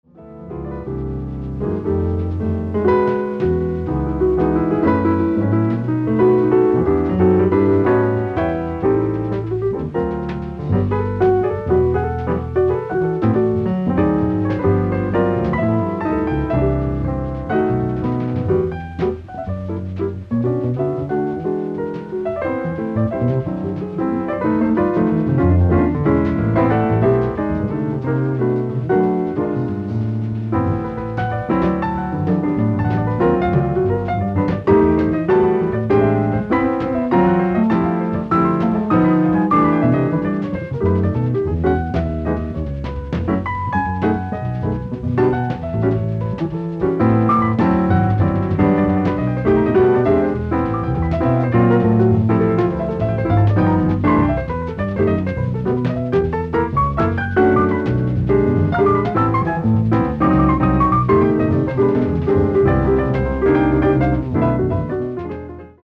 ライブ・アット・モントリオール、カナダ 01/13/1980
※試聴用に実際より音質を落としています。